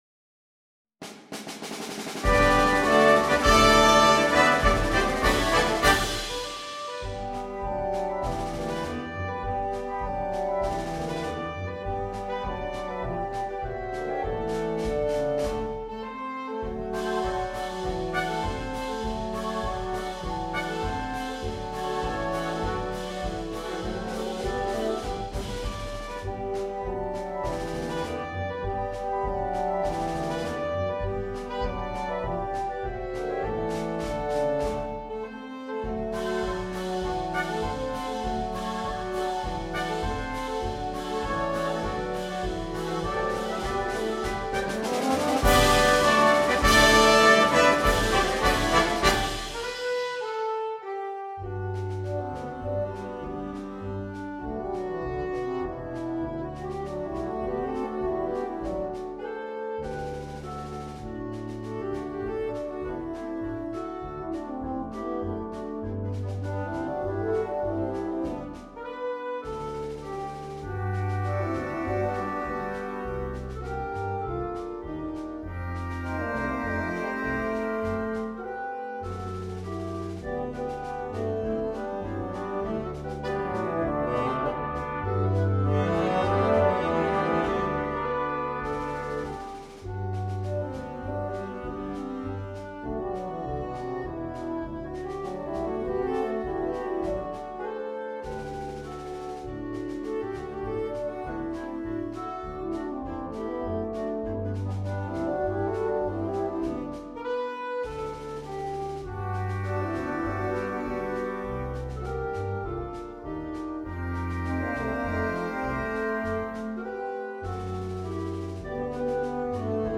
Himne